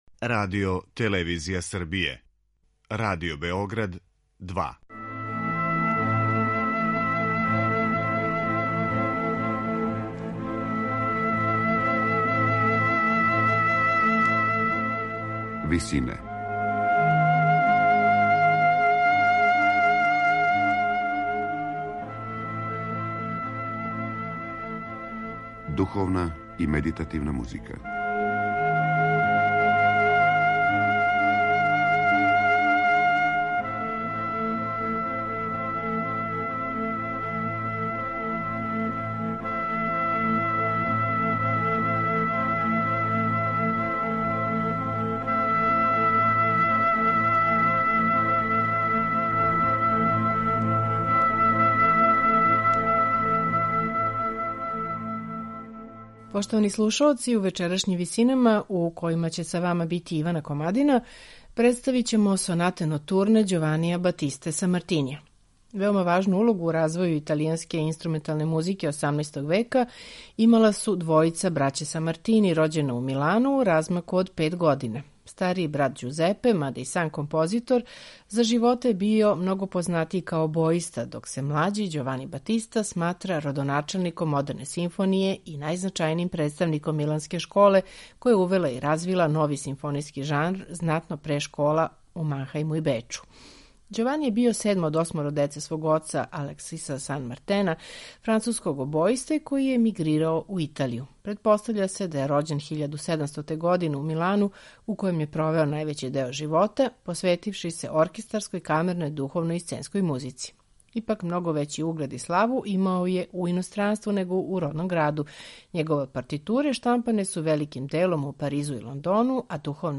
На крају програма, у ВИСИНАМА представљамо медитативне и духовне композиције аутора свих конфесија и епоха.
У вечерашњим Висинама представићемо једно позно и сасвим необично Самартинијево дело - збирку „Sonate notturne" опус 7. Слушаћете их у интерпретацији ансамбла „Accademia Farnese".